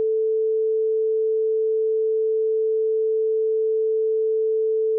OGG Sample - 5 Second Tone OGG 12 KB 0:05 audio/ogg Vorbis Download example file